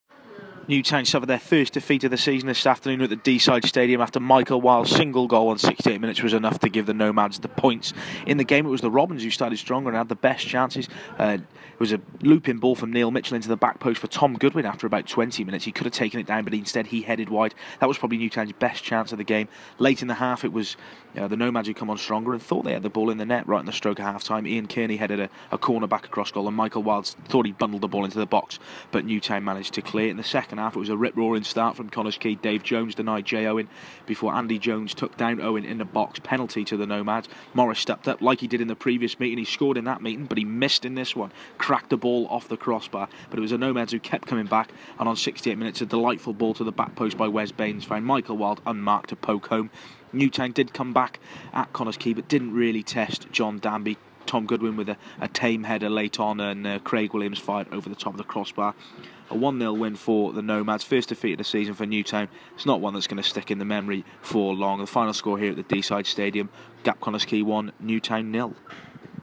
AUDIO REPORT - Nomads 1-0 Robins